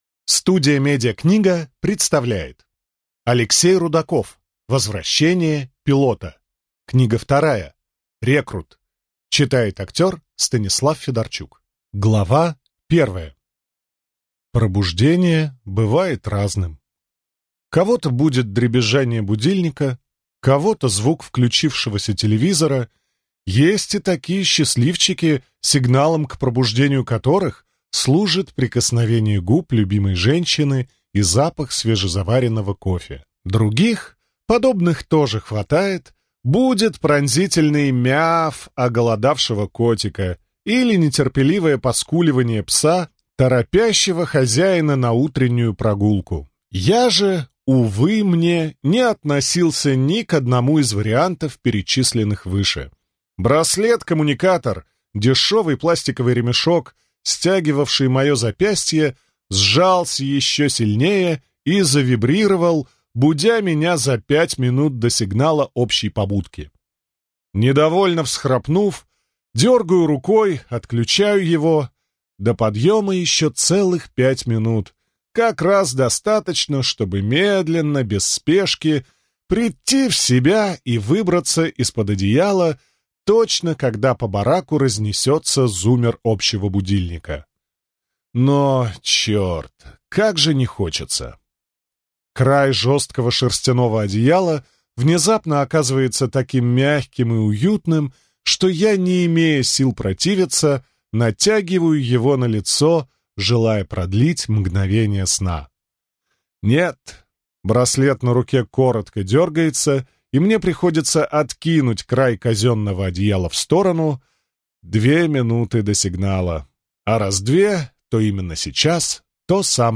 Аудиокнига Рекрут | Библиотека аудиокниг